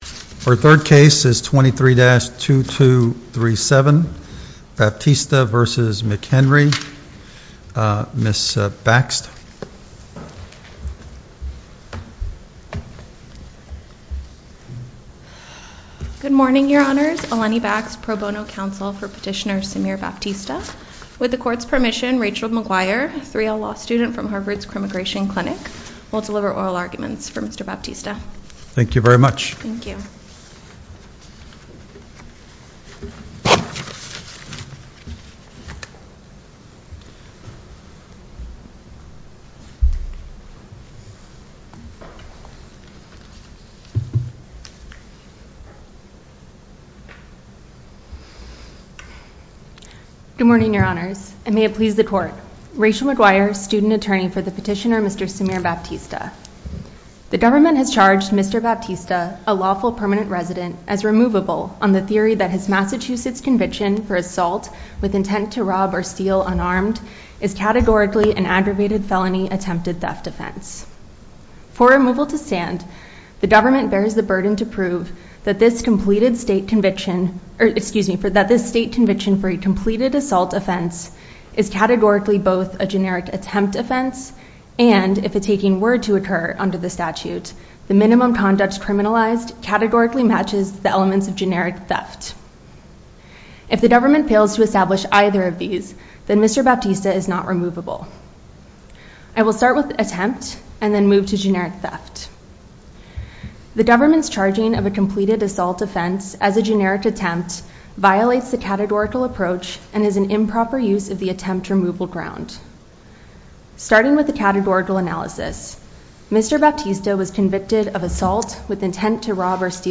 Oral Arguments for the Court of Appeals for the Fourth Circuit
A chronological podcast of oral arguments with improved files and meta data.